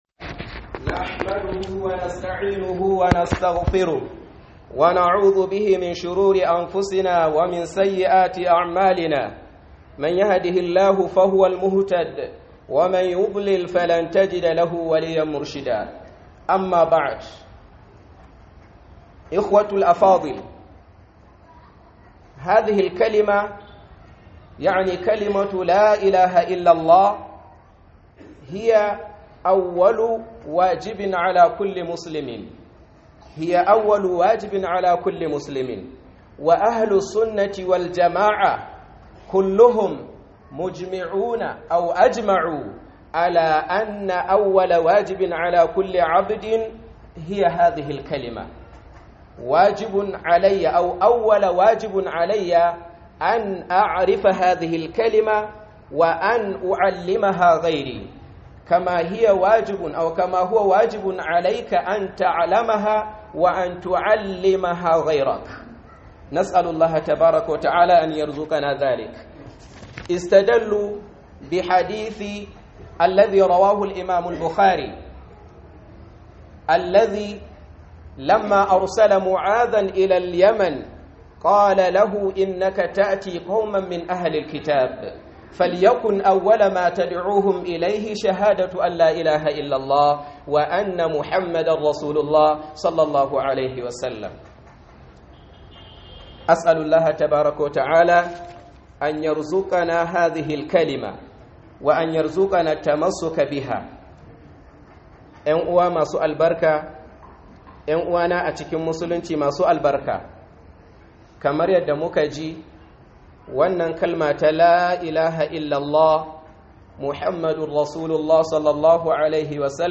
KHUDUBAR JUMA'AH